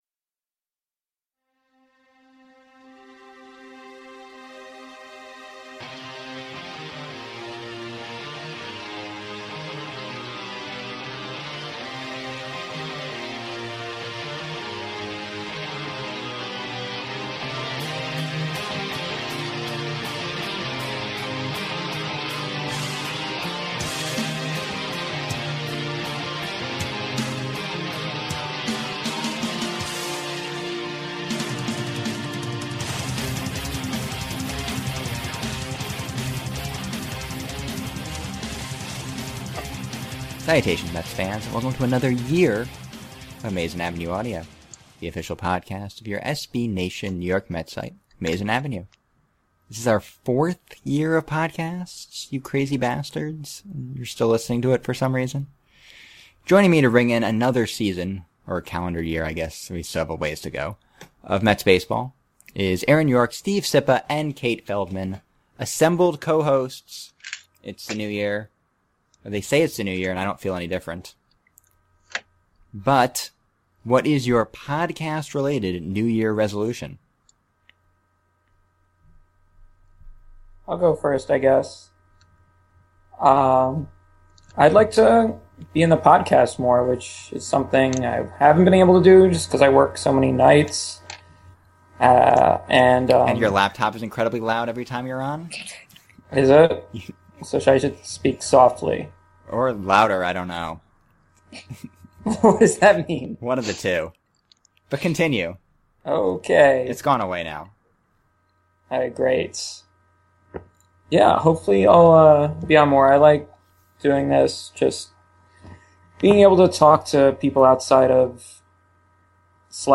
laptop is very noisy
this was recorded on a now ten-yer-old MacBook